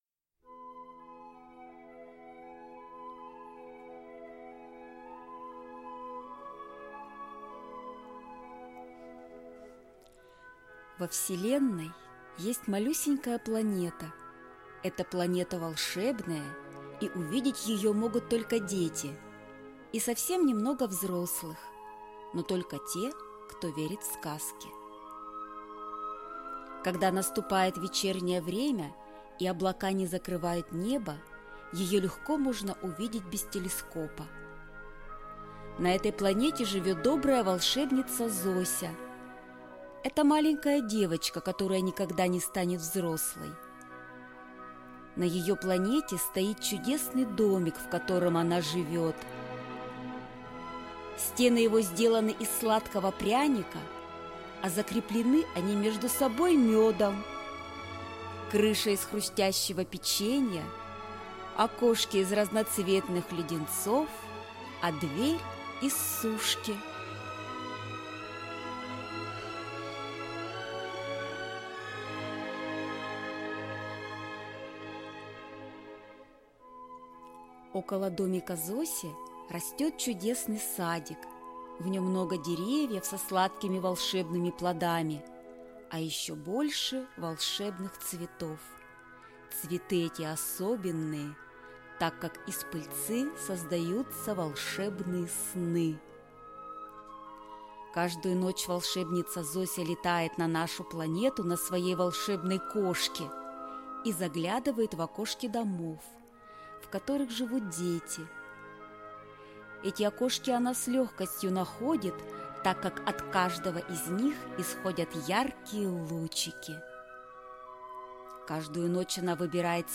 Аудиокнига Зося спасает планету | Библиотека аудиокниг